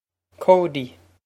Pronunciation for how to say